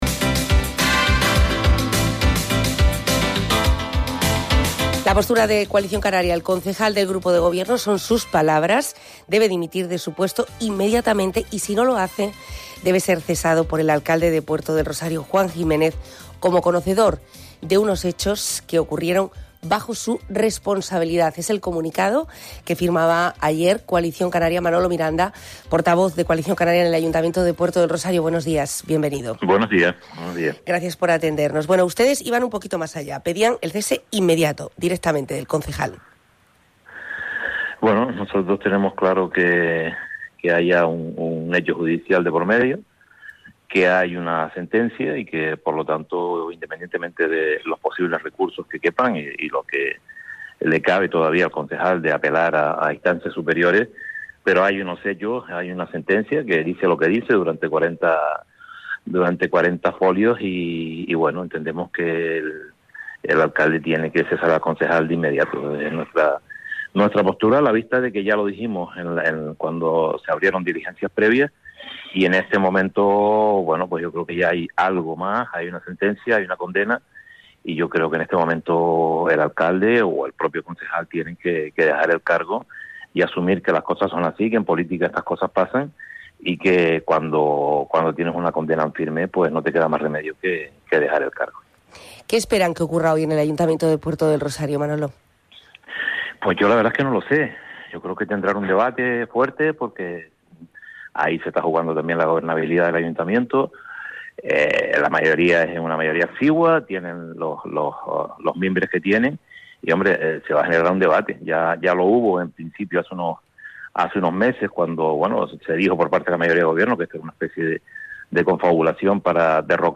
El portavoz de Coalición Canaria en el Ayuntamiento de Puerto del Rosario Manolo Miranda ha estado en los micrófonos de Onda Fuerteventura. En El Magacín ha hablado de la dimisión o cese inmediato de Yose Herrera después de conocerse la sentencia en el día de ayer por el que ha sido condenado por acoso sexual.